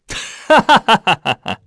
Lusikiel-Vox_Happy3_kr.wav